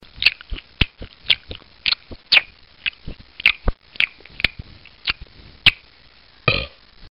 SFX吃东西吧唧嘴的声音音效下载